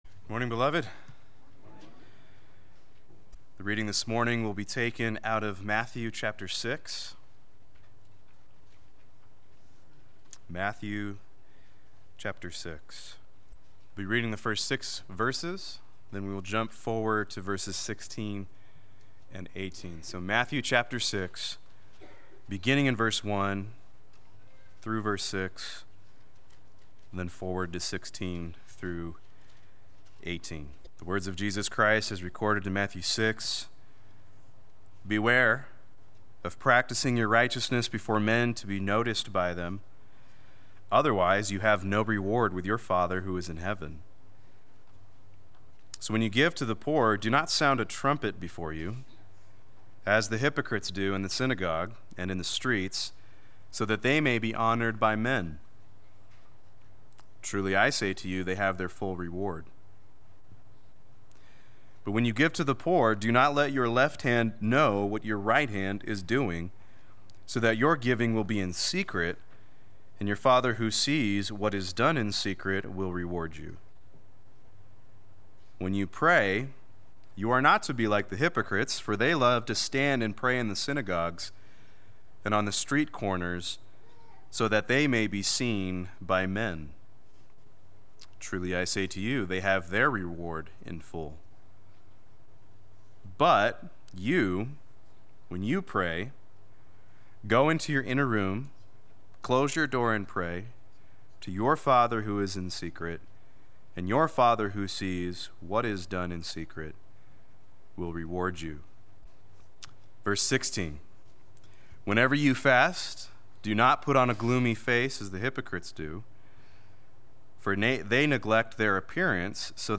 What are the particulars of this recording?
The Worship God Rewards Sunday Worship